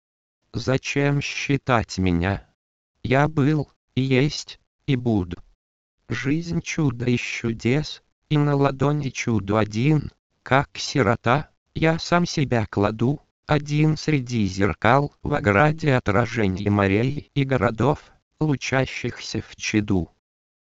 Интересно, можно ли как-то вставлять КОРОТКИЕ паузы в концах стихотворных строк, если там, в концах, нет знаков препинания? Николай почти всегда не оставляет НИКАКИХ пауз в таких случаях.